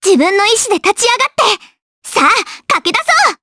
Estelle-Vox_Skill6_jp.wav